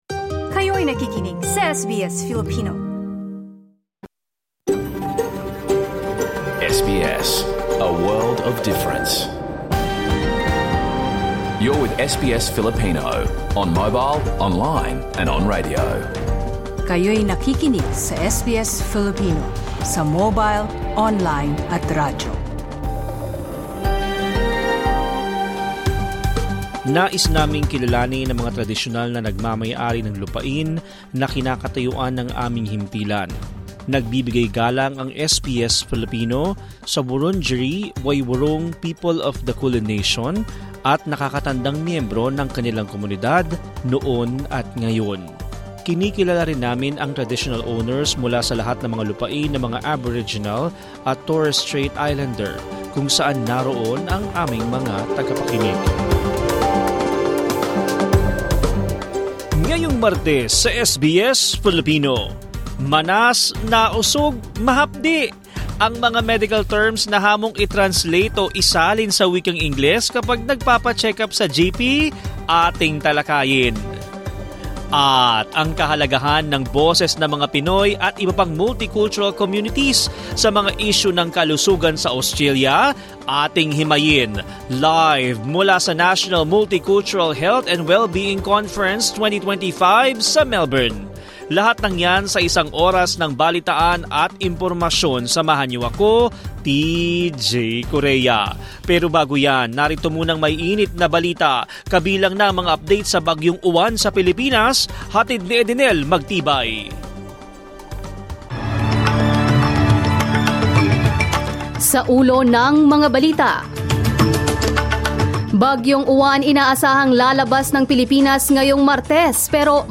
LIVE at FECCA 2025 Multicultural Health and Wellbeing Conference — SBS Language Radio programs, including the Filipino program, broadcasted live to highlight key conversations on health issues and CALD representation in Australia’s health system.